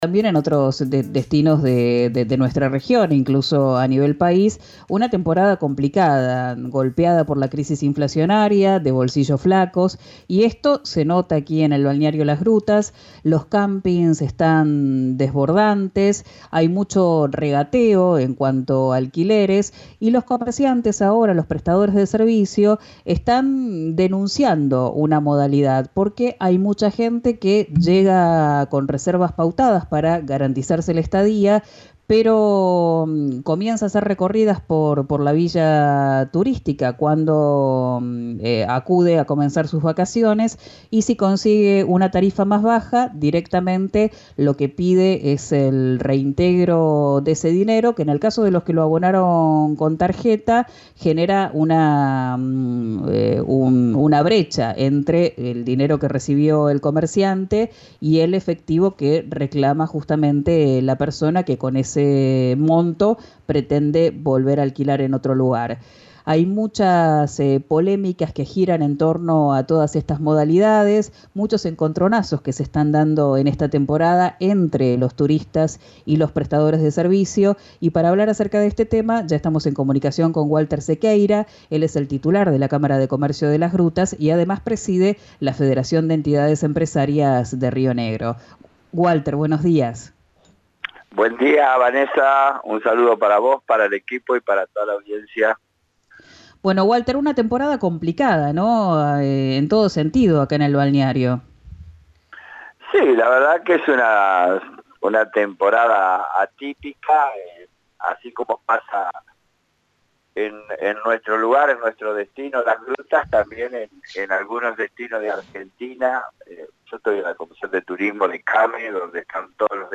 Lo hizo en diálogo con Quien Dijo Verano, por RÍO NEGRO RADIO, donde adelantó que la entidad trabajará para impulsar una iniciativa por la que ya están reclamando a nivel país.